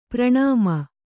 ♪ praṇāma